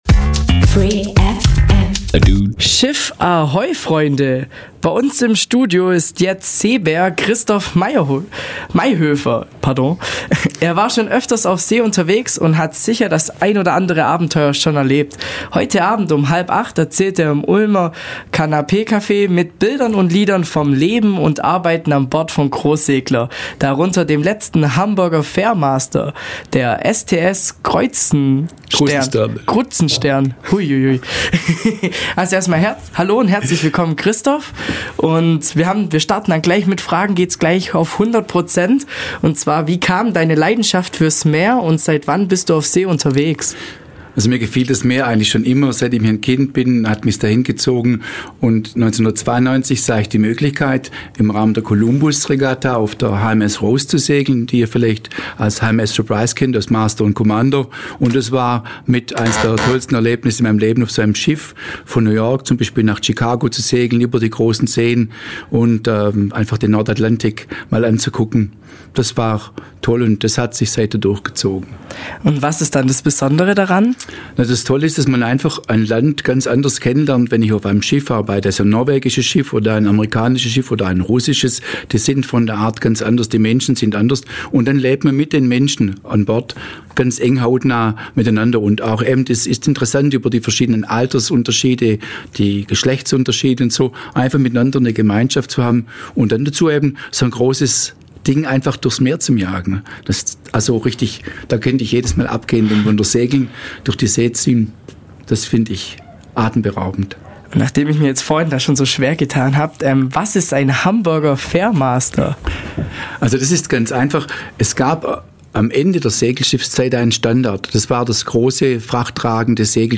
Wir sprechen mit ihm über das Meer, über Seefrauen und -männer, Aberglaube und Abenteuer.
Radio